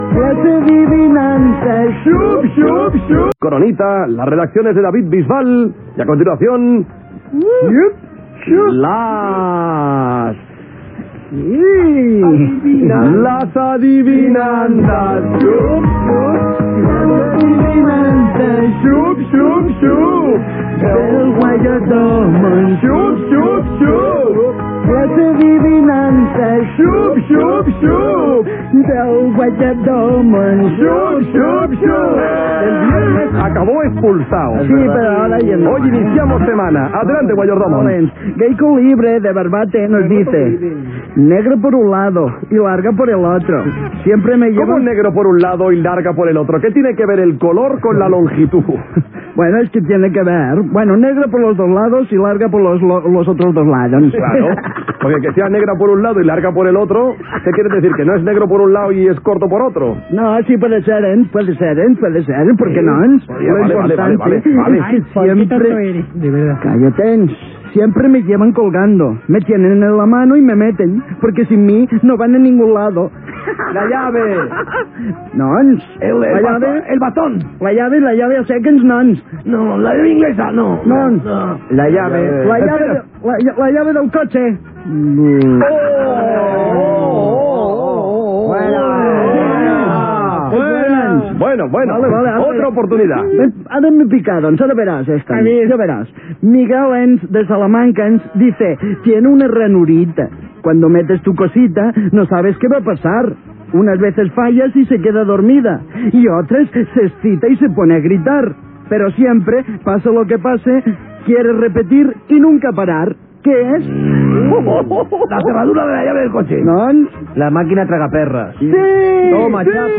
Entreteniment
FM
Paròdia de Paul Burrell, qui va ser el majordom de la corona britànica durant 21 anys.